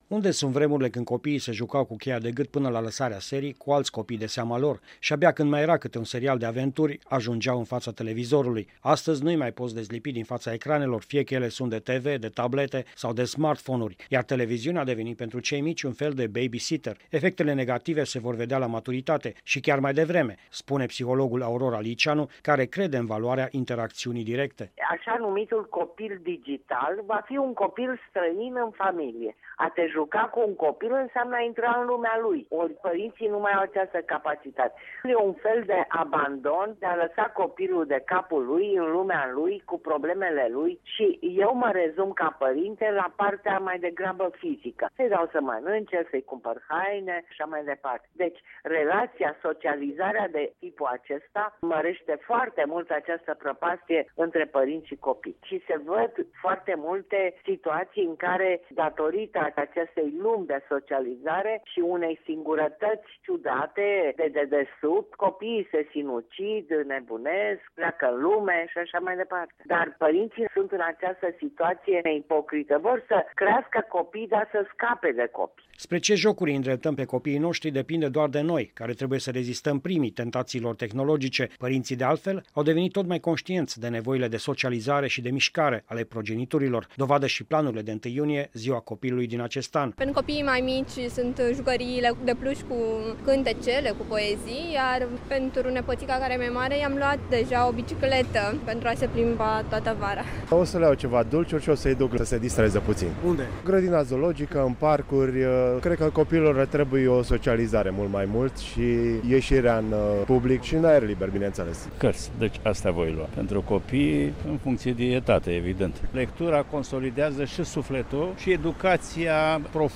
a realizat un reportaj pe această temă